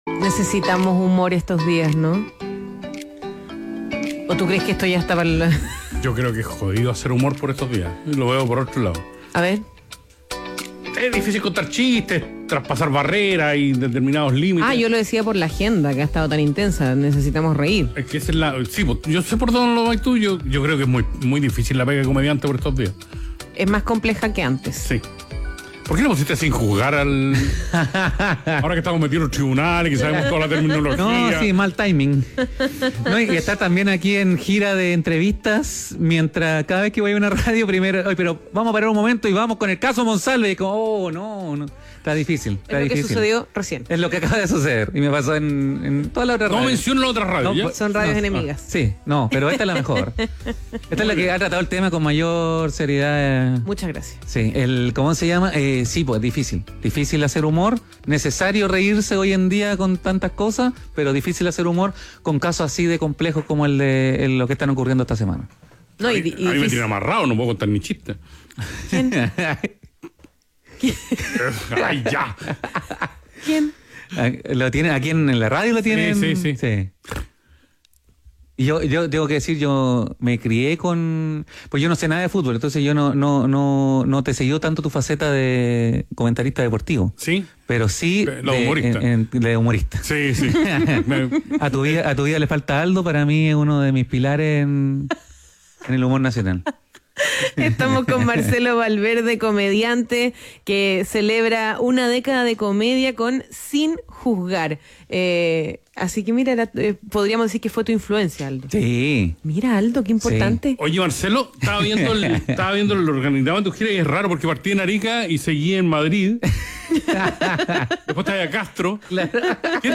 País ADN - Entrevista a Marcelo "Coronel" Valverde